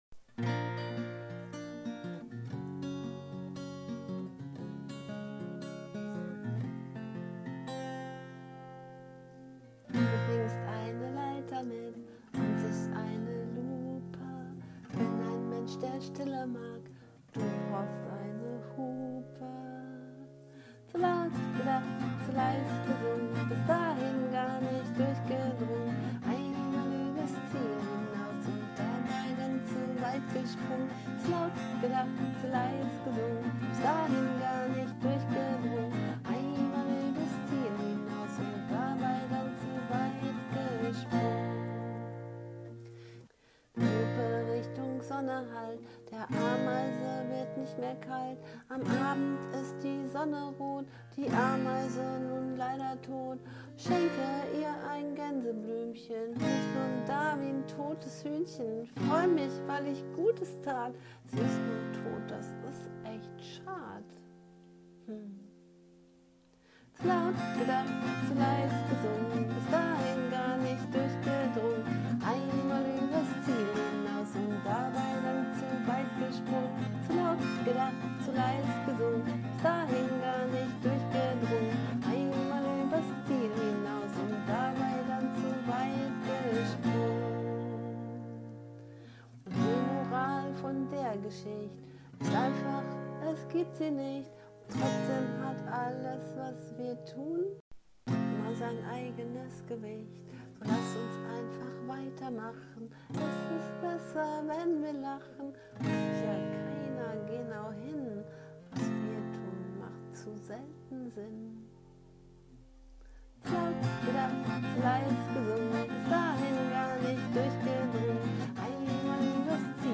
Ist auch nicht so perfekt gesungen und eben nur mit dem Handy aufgenommen.
: ) Also, ist ja eine Art Kinderlied.